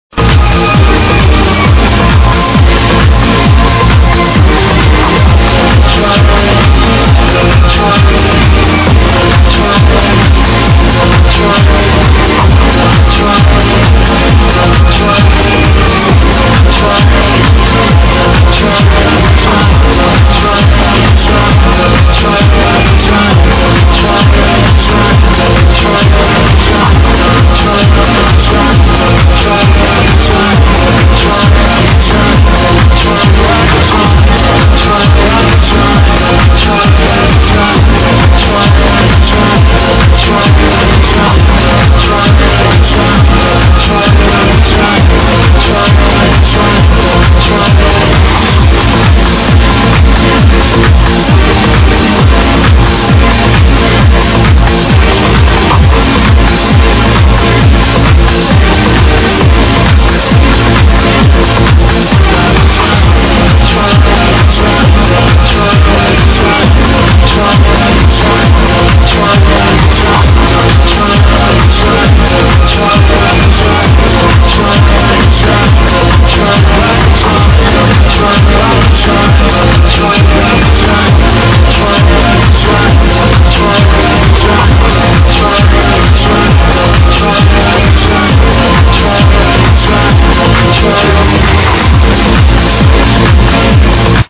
catchy tune...
sounds like hes saying china over and over.